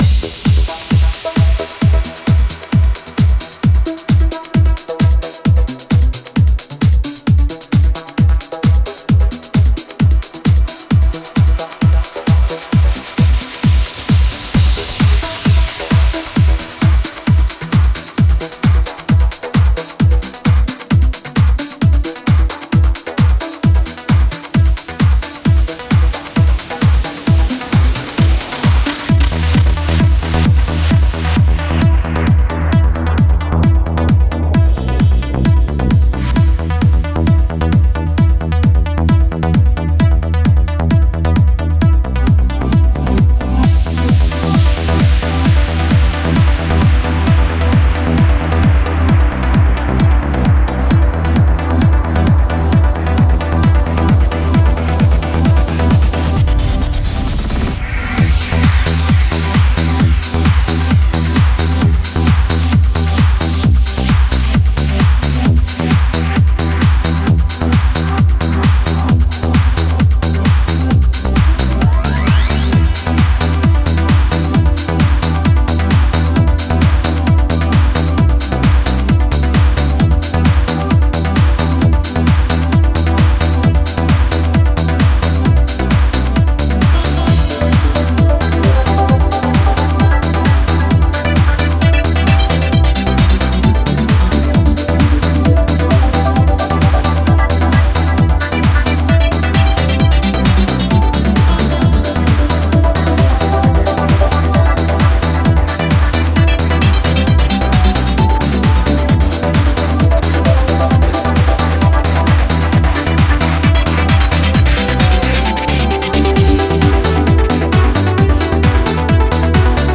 Genre: Bass.